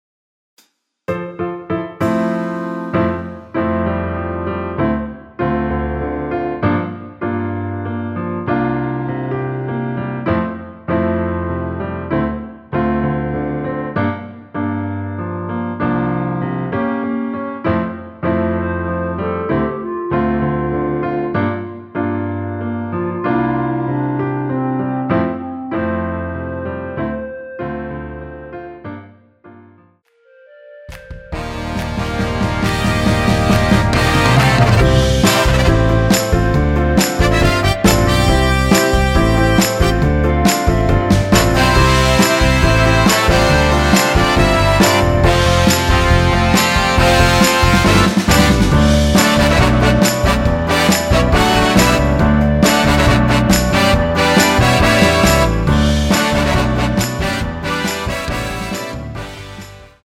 전주 없이 시작 하는 곡이라 인트로 만들어 놓았습니다.
원키에서(-2)내린 멜로디 포함된 MR입니다.
앞부분30초, 뒷부분30초씩 편집해서 올려 드리고 있습니다.
중간에 음이 끈어지고 다시 나오는 이유는